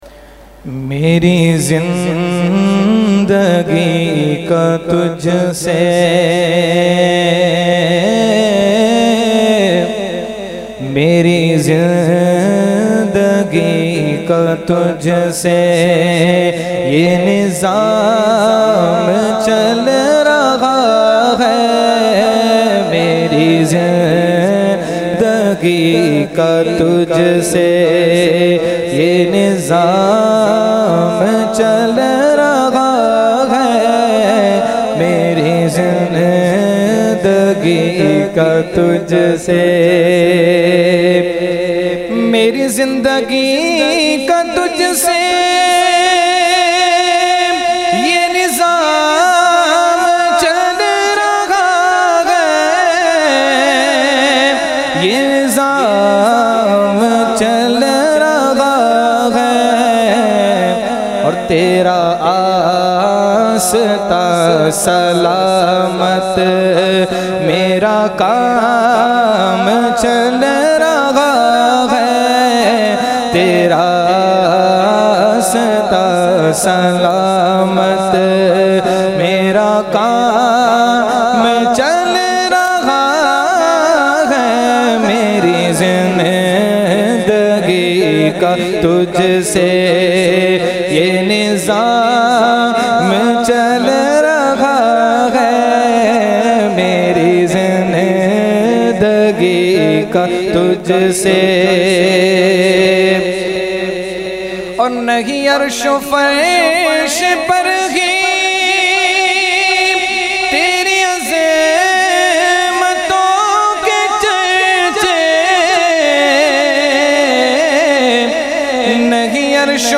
Category : Naat | Language : UrduEvent : Urs Ashraful Mashaikh 2020